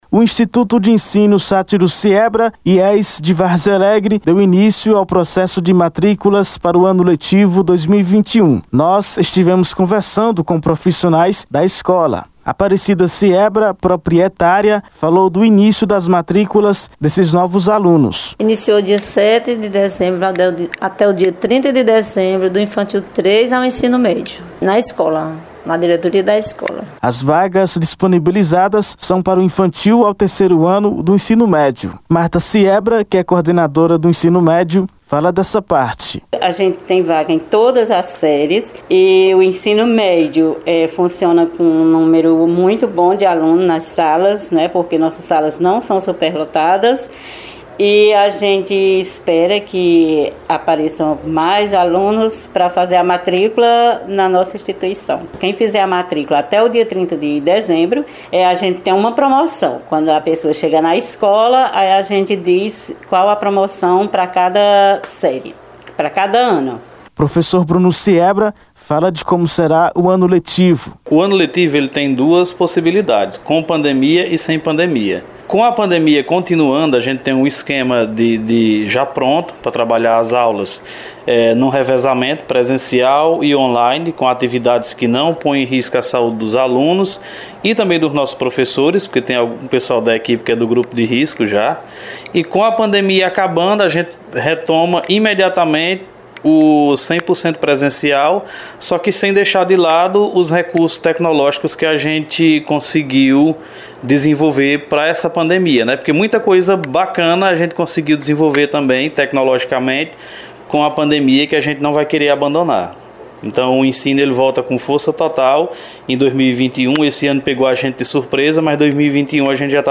Nós estivemos conversando com profissionais da escola.
Confira no áudio da reportagem: